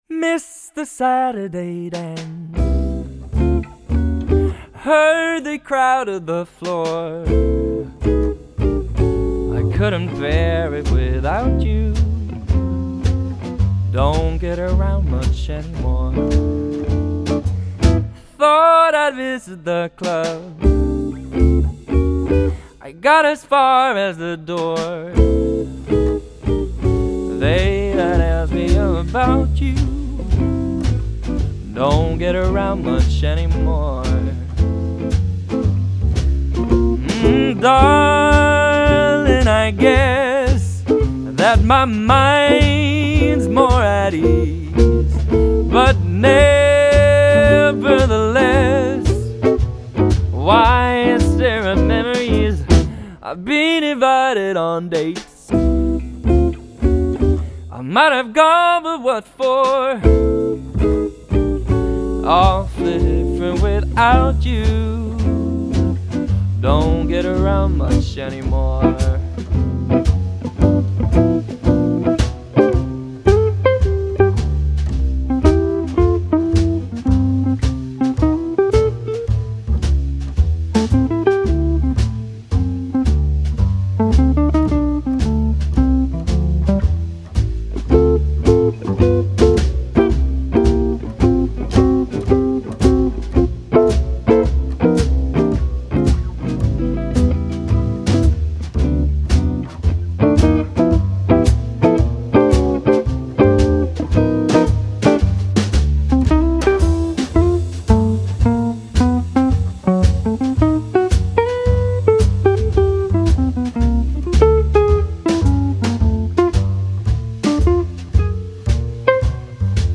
Jazzkvartetten
elegant klassisk vokaljazz